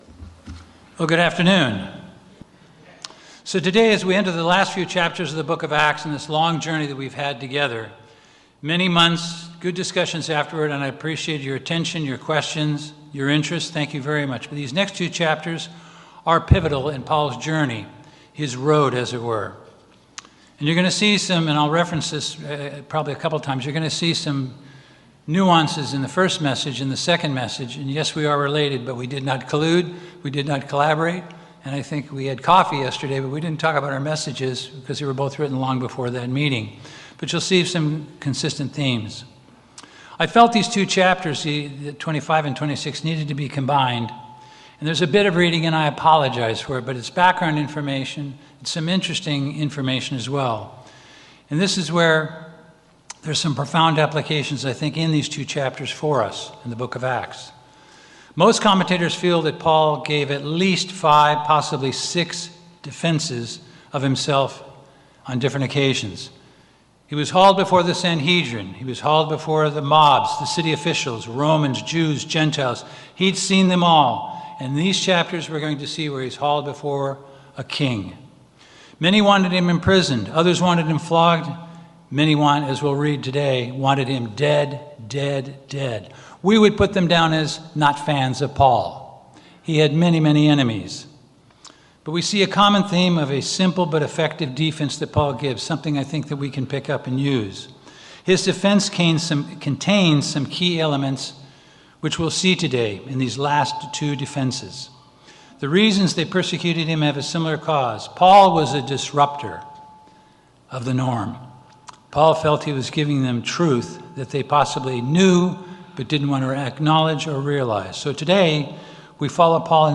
Sermons
Given in Los Angeles, CA Bakersfield, CA